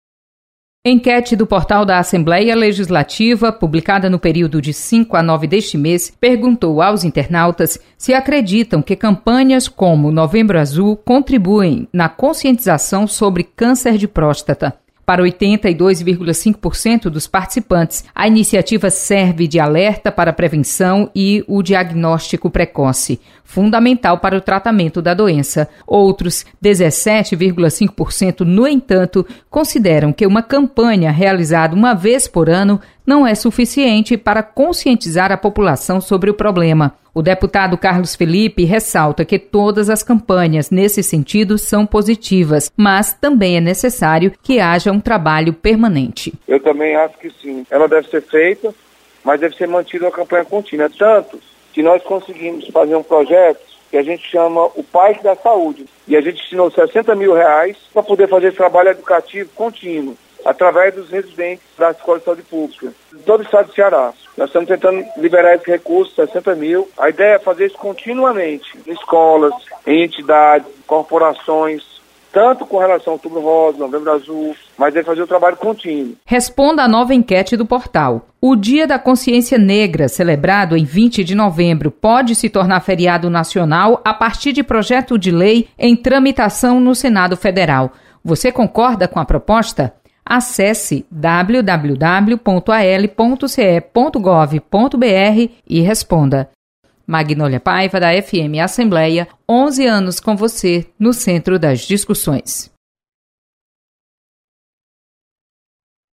Enquete